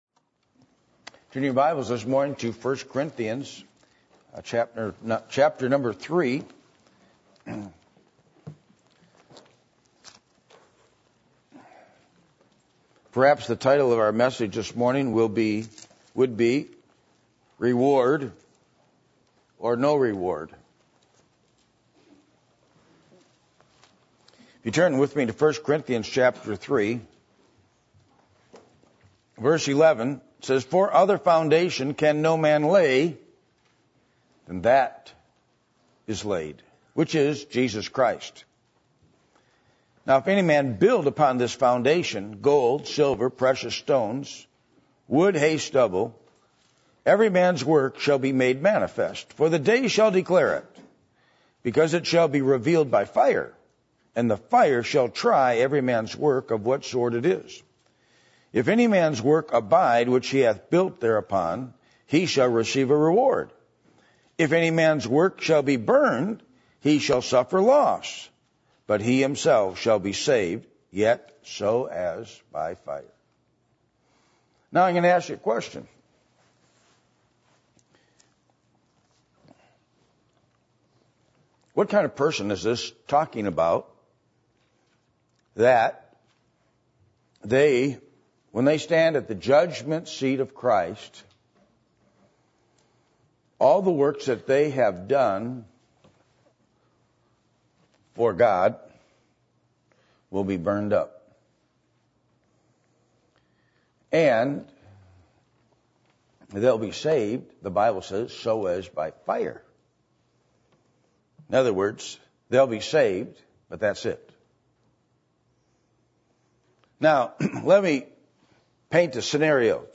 Passage: 1 Corinthians 3:11-15 Service Type: Sunday Morning %todo_render% « What Are You Doing With The Blessings You’ve Been Given?